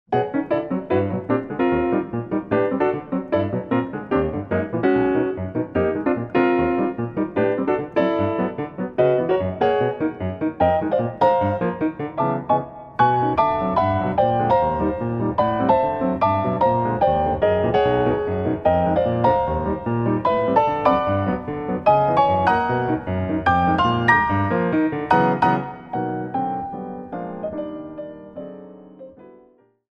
piano solo -